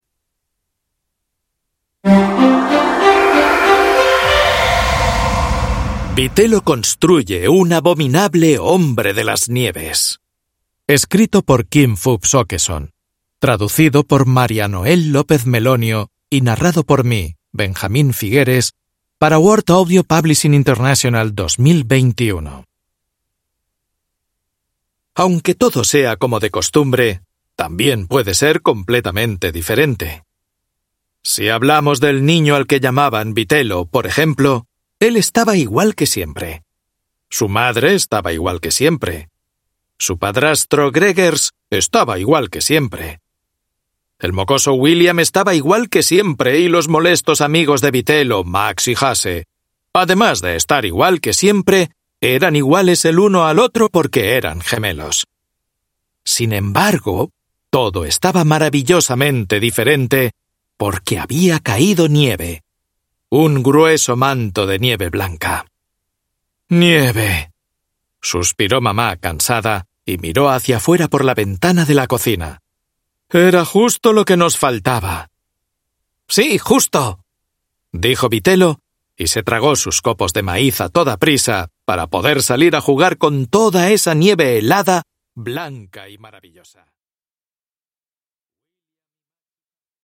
Vitello construye un abominable hombre de las nieves (ljudbok) av Kim Fupz Aakeson